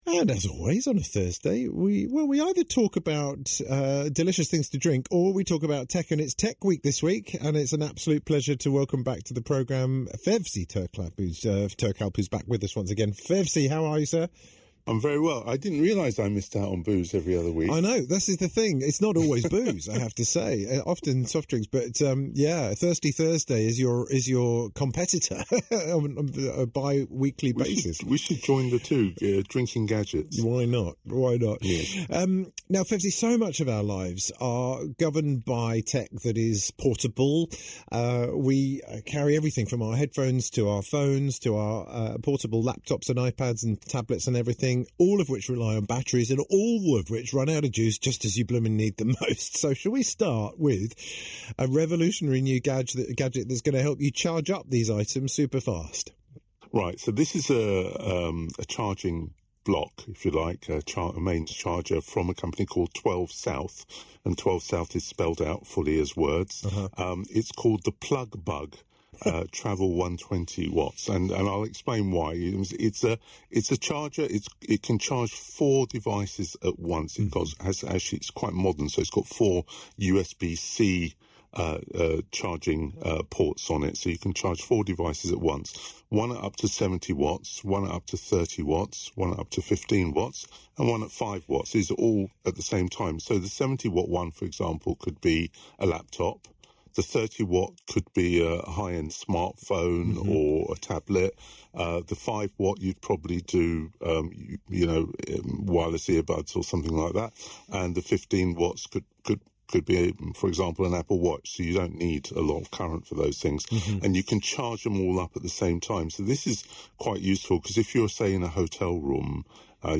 12th June 2025 - Tech Reviews on BBC Radio London